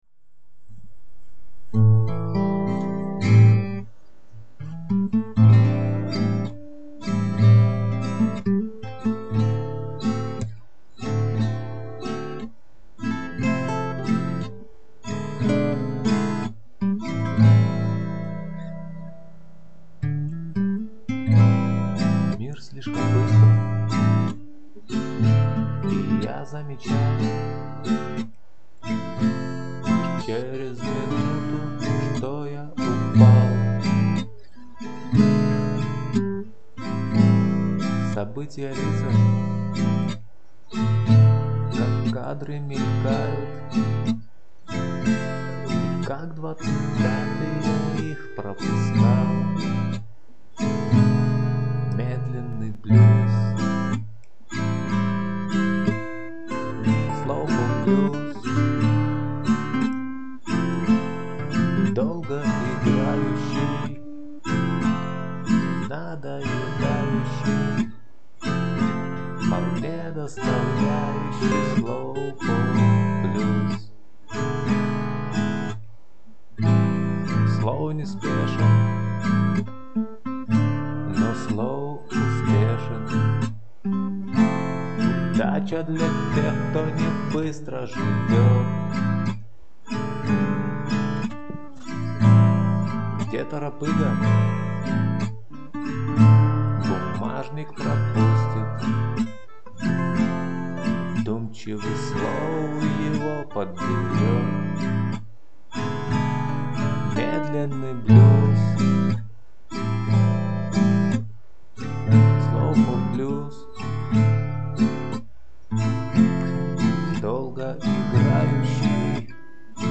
Slowpoke-Blues-2011.mp3